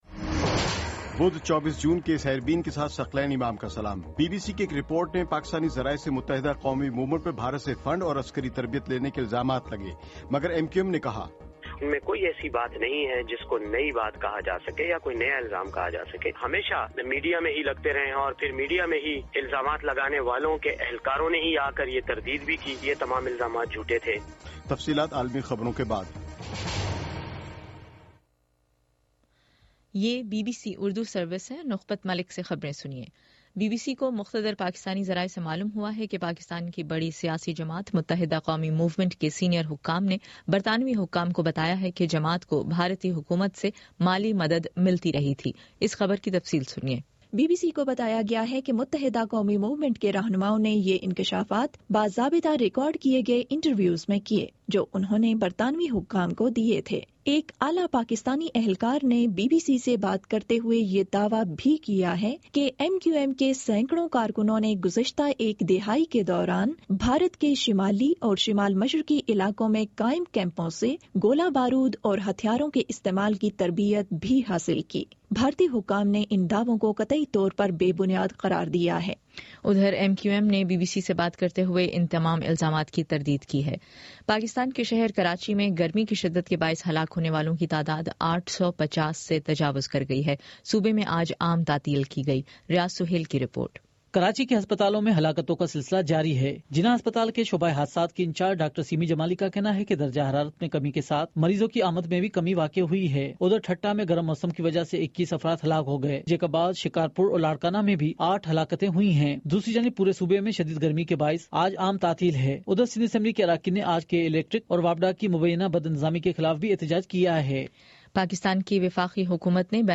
بدھ 24 جون کا سیربین ریڈیو پروگرام